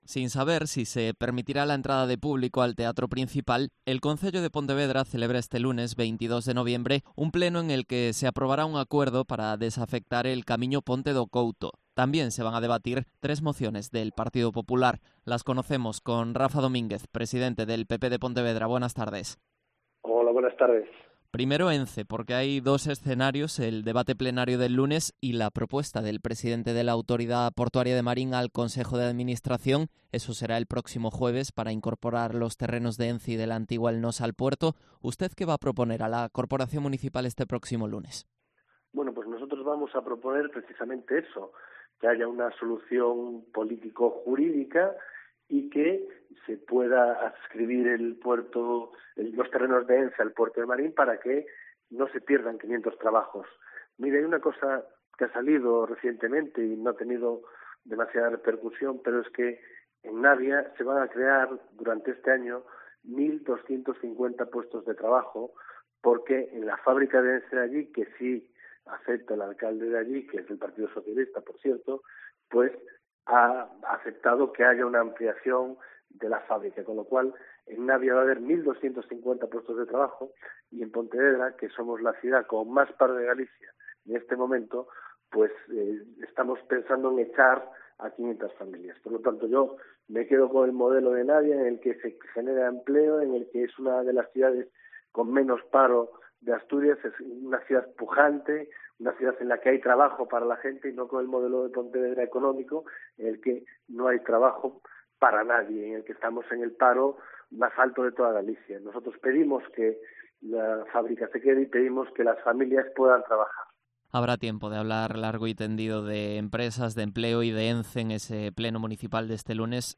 Entrevista a Rafa Domínguez, presidente del PP de Pontevedra